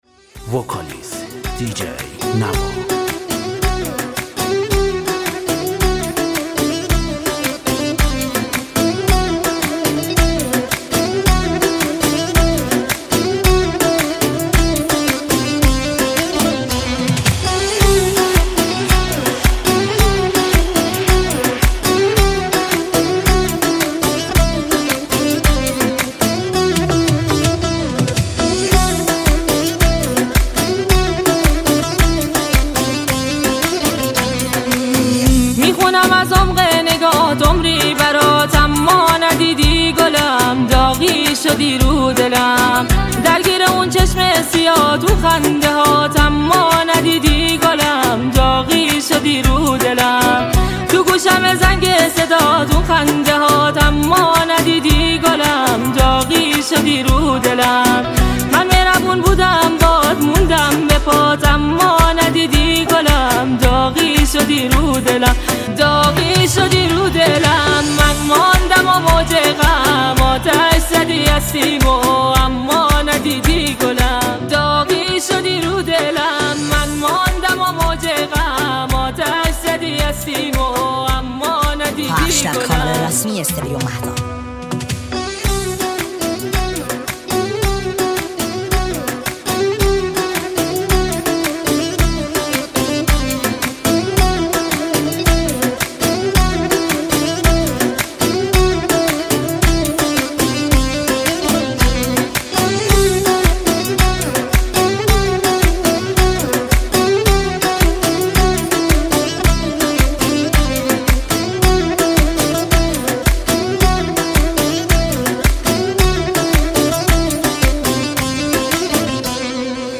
صدای زن